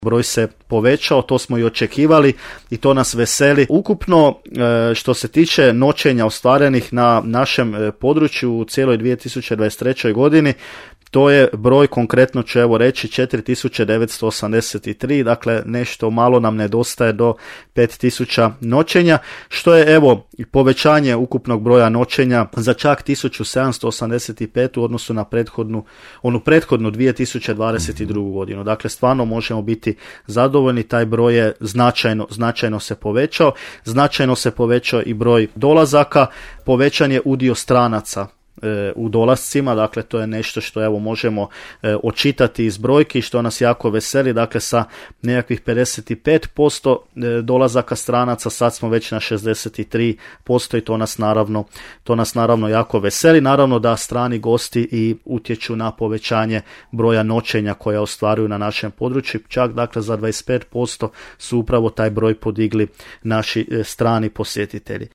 Gost emisije „Susjedne općine” u programu Podravskog radija